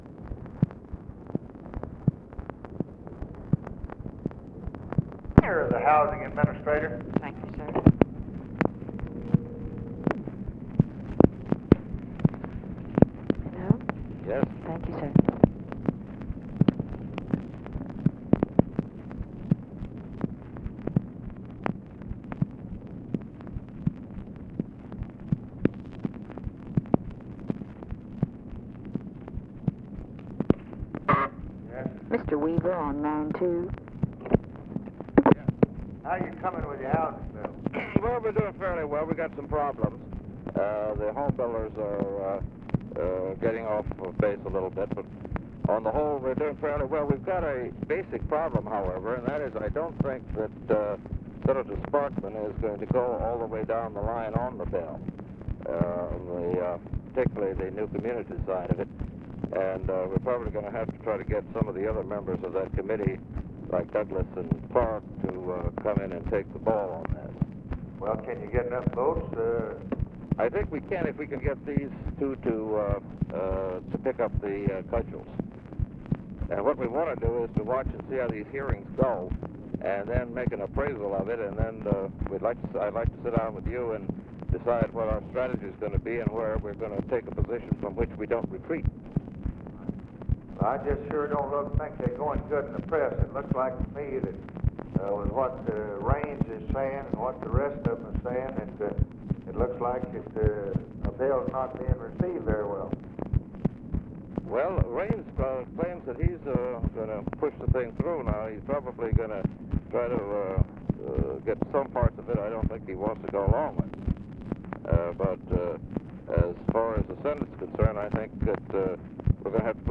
Conversation with ROBERT WEAVER, February 25, 1964
Secret White House Tapes